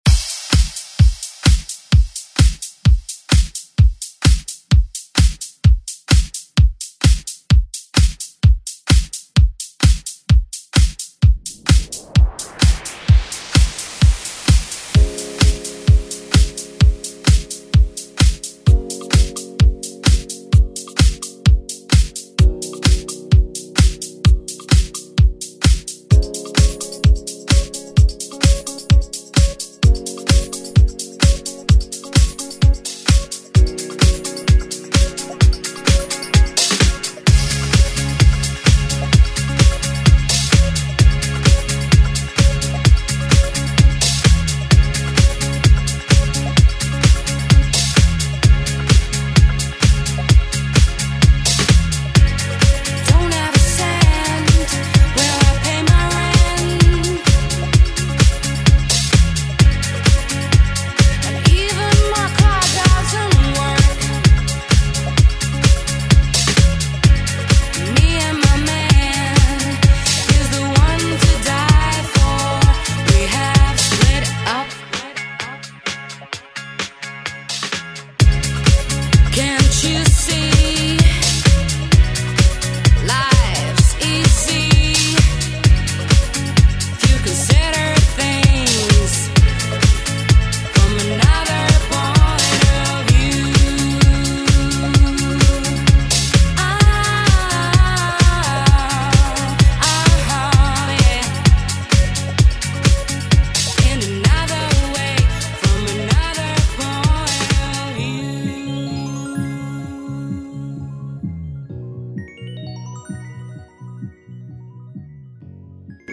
115 bpm
Genre: 80's